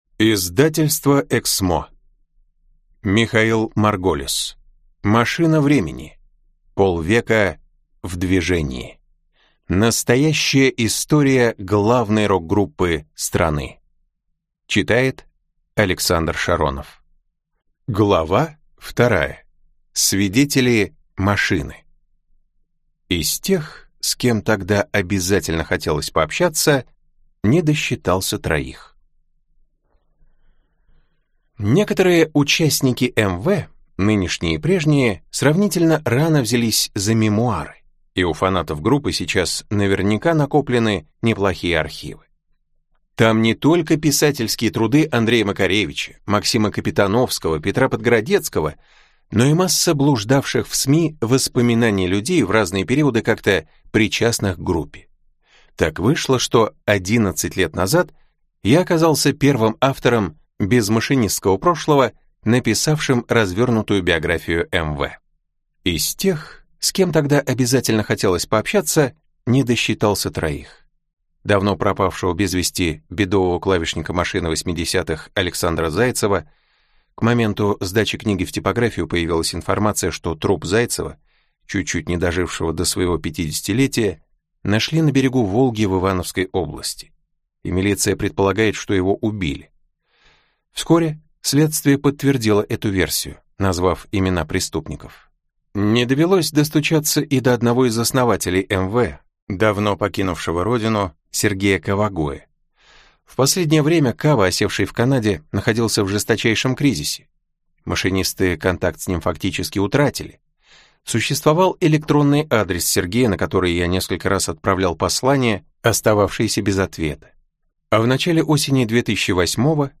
Аудиокнига Машина Времени. Полвека в движении | Библиотека аудиокниг